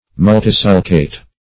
Multisulcate \Mul`ti*sul"cate\, a.